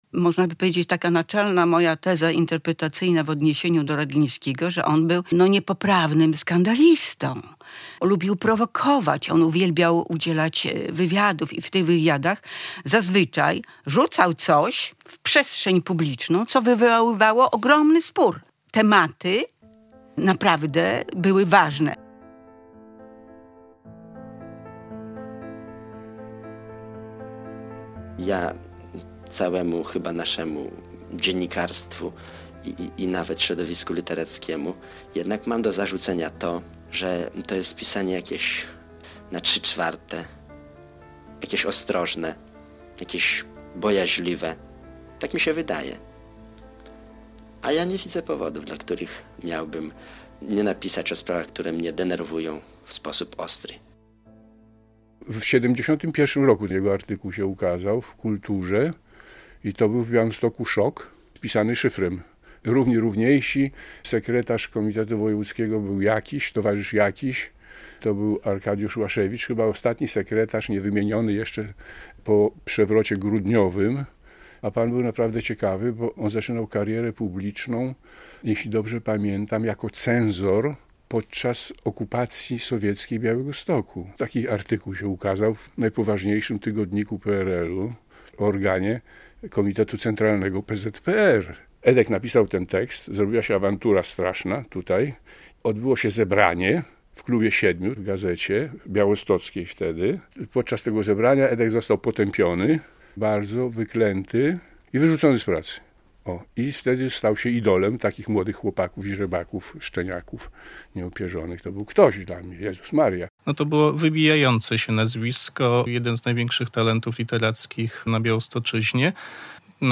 Rodzina, bliscy i czytelnicy pożegnali Edwarda Redlińskiego - relacja